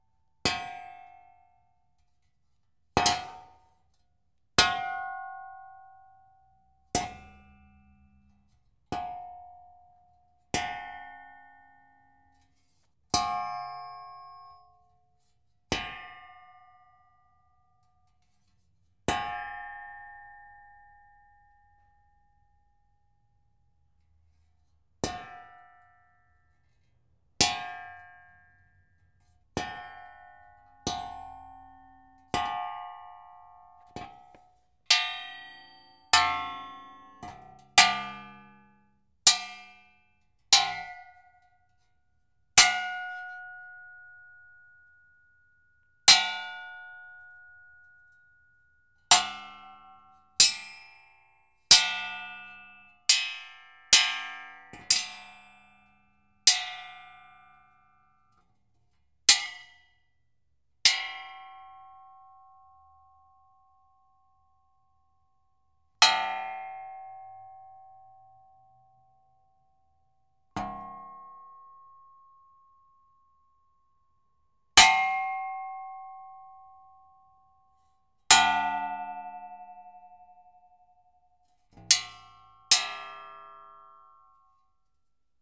Metal_Hit_01
clang clank ding hit metal ping resonance ringing sound effect free sound royalty free Sound Effects